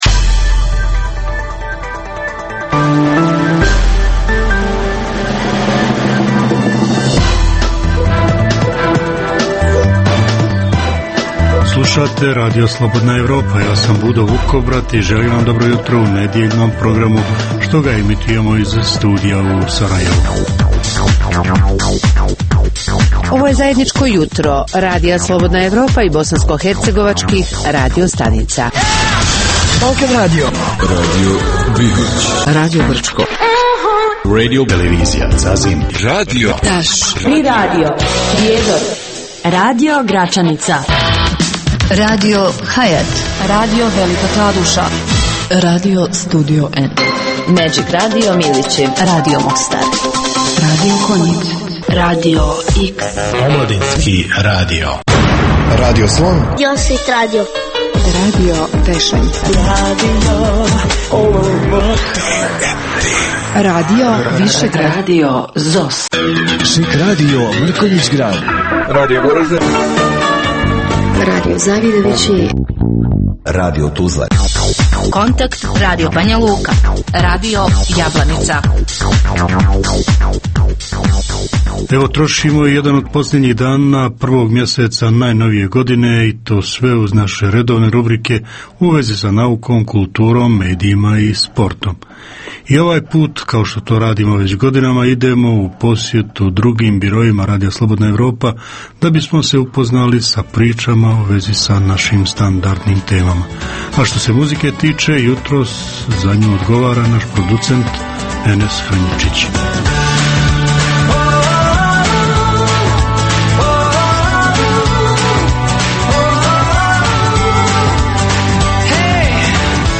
Jutarnji program namijenjen slušaocima u Bosni i Hercegovini. Kao i obično, uz vijesti i muziku, poslušajte pregled novosti iz nauke i tehnike, te čujte šta su nam pripremili novinari RSE iz Zagreba i Beograda.